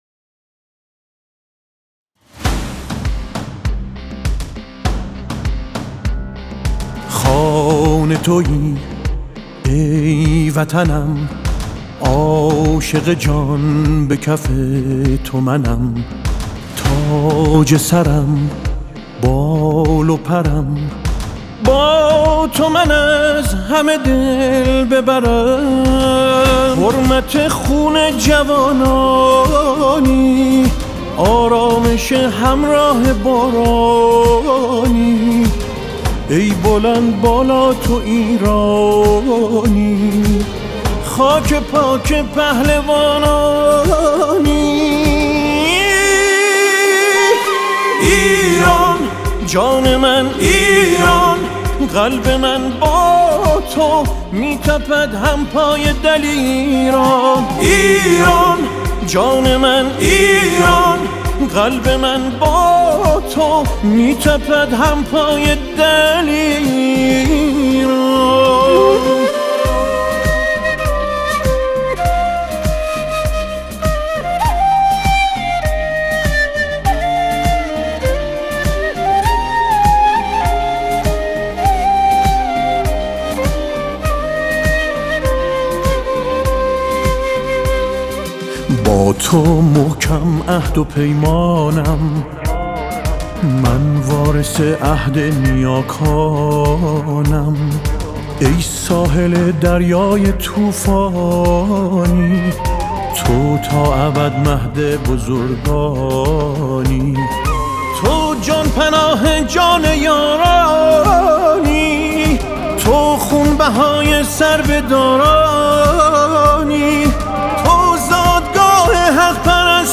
دانلود آهنگ حماسی